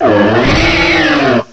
sovereignx/sound/direct_sound_samples/cries/chesnaught.aif at master